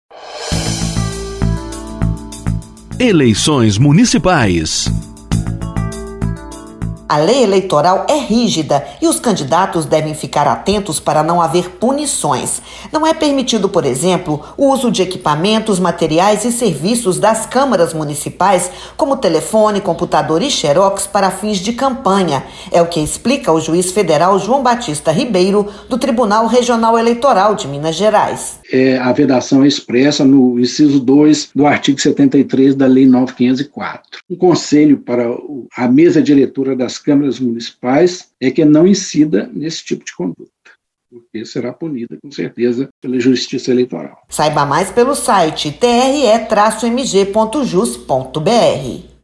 Ouça a dica do Juiz Federal, João Batista Ribeiro.